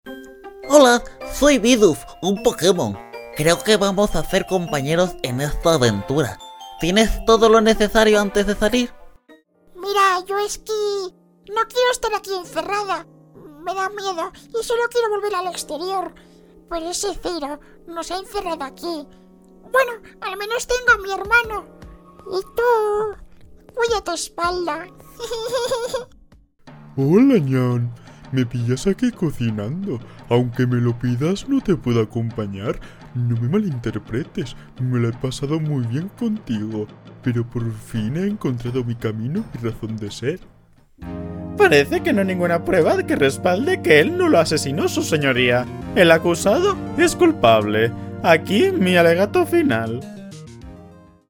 Demo voces
Castellano neutro
Young Adult